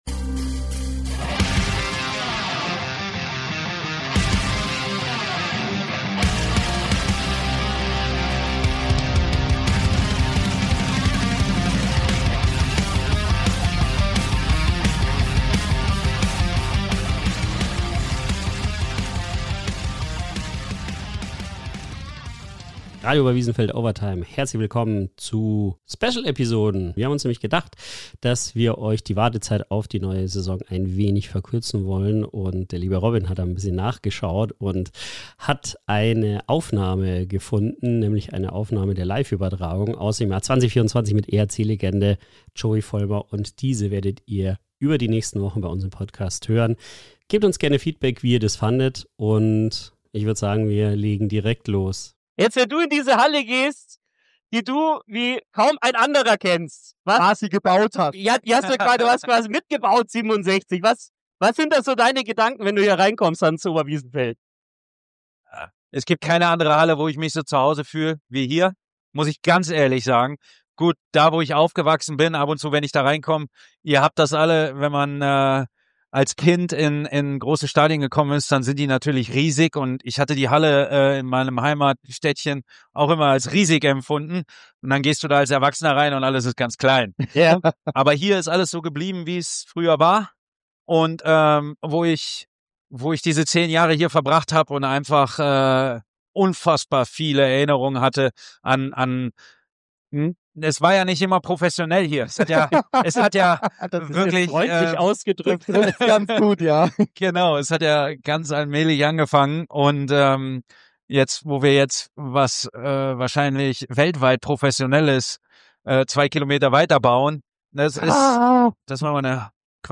Original-Radioübertragung aus dem Jahr 2024
Emotionale Rückblicke, Spielanalysen & einmalige Atmosphäre